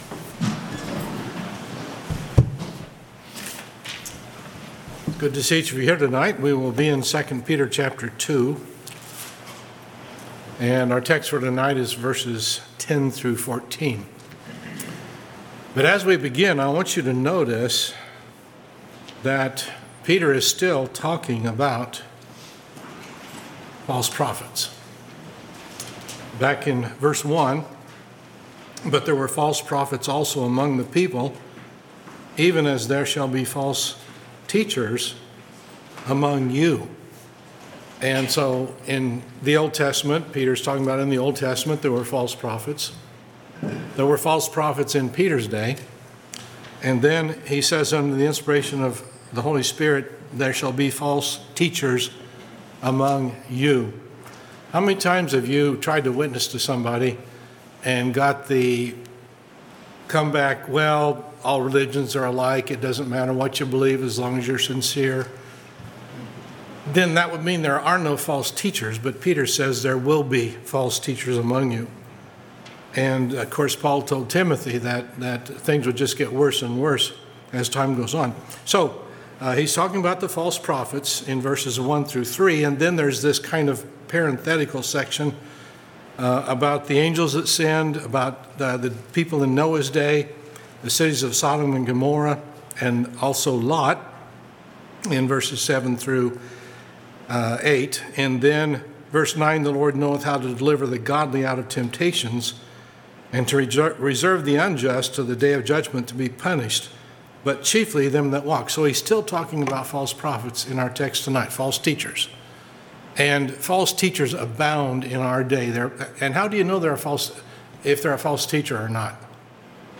Sermons by Faith Baptist Church